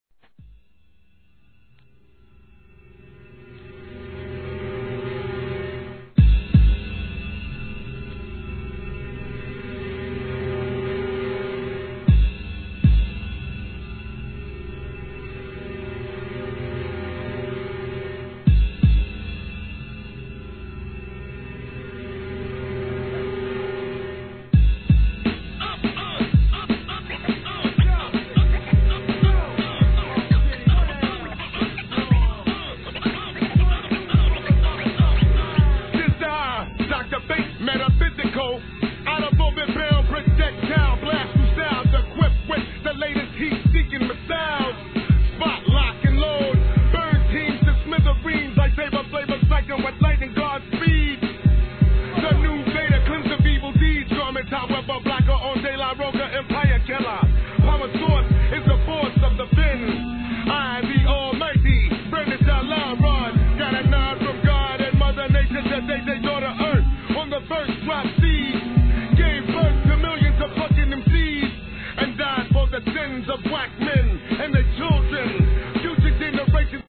HIP HOP/R&B
そのサウンドはスリリング極まりない!